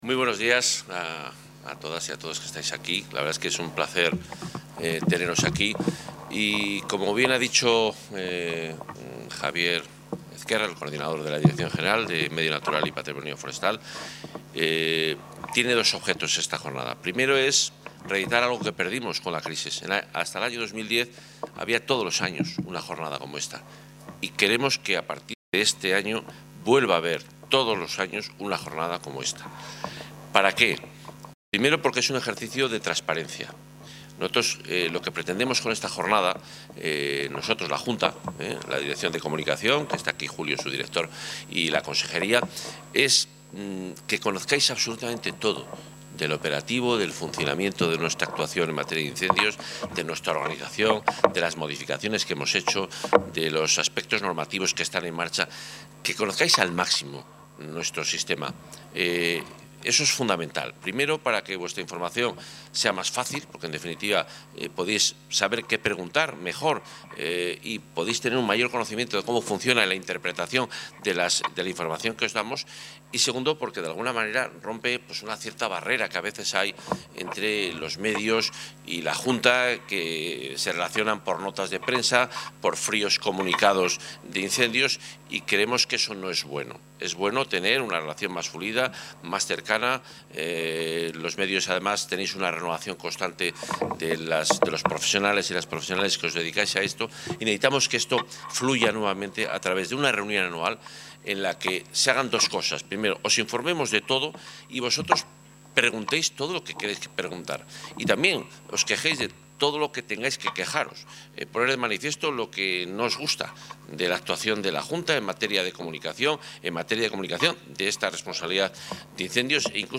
Intervención del Consejero (11.875 kbytes).